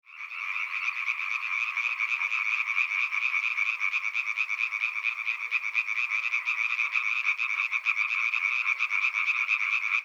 Stemme:
Ved skumringstid starter løvfrøerne deres koncert med hurtige, gentagne kvæk. Løvfrøens stemme adskiller sig meget fra de øvrige nordiske frøer. Der er nærmest noget tropeagtigt over løvfrøens cikadelignende kvækken.
treefrog.mp3